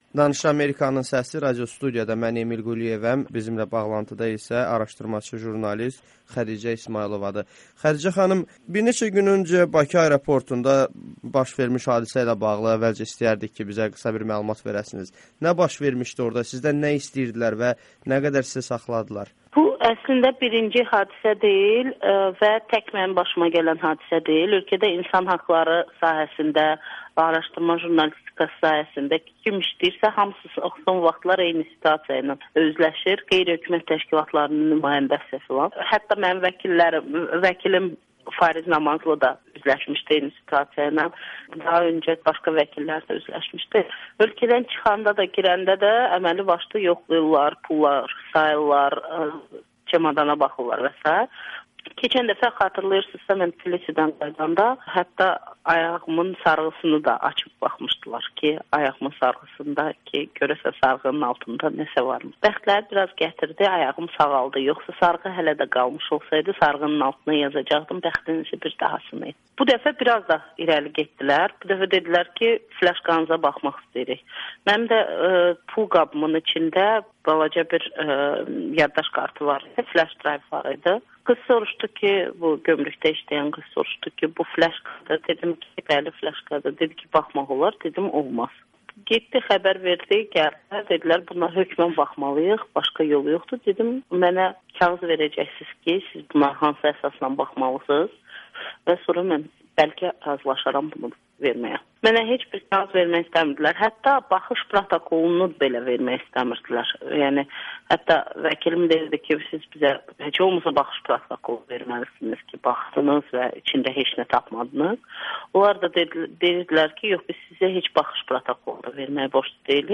Xədicə İsmayılovanın Amerikanın Səsinə müsahibəsi